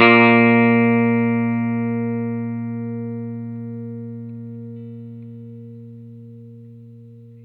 R12 NOTE  BF.wav